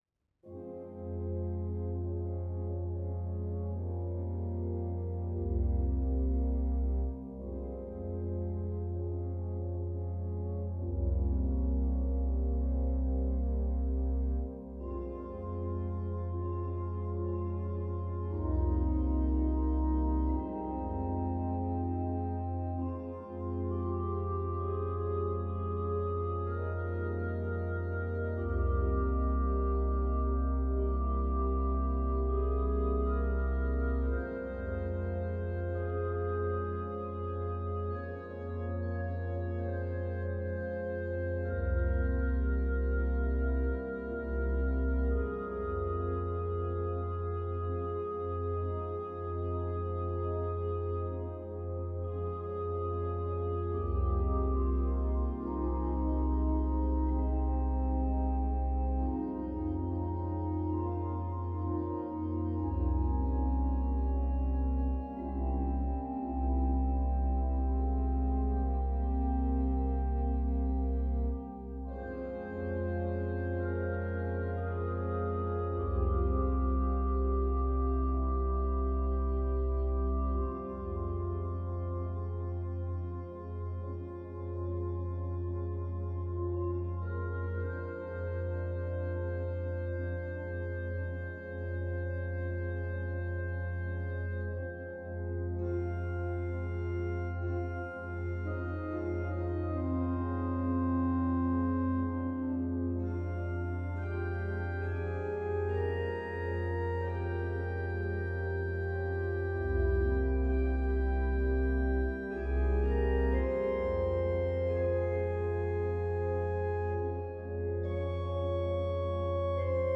Voicing: Organ